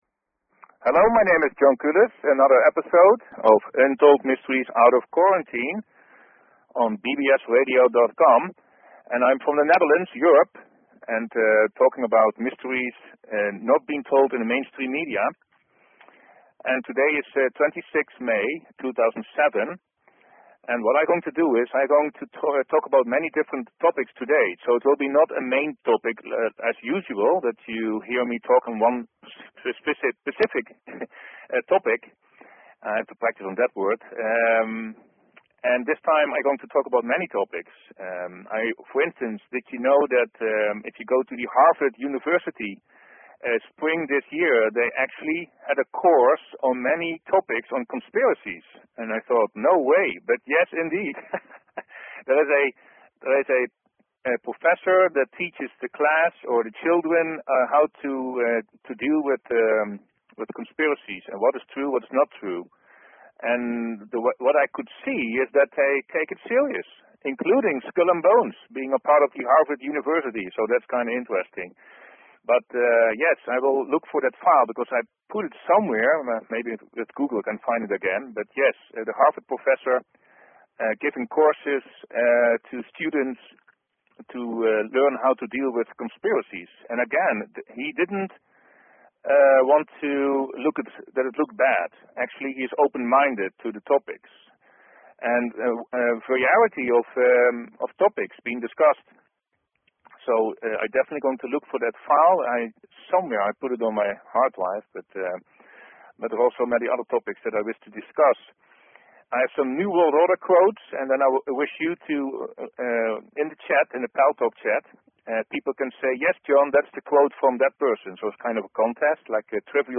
Talk Show Episode, Audio Podcast, UntoldMysteries and Courtesy of BBS Radio on , show guests , about , categorized as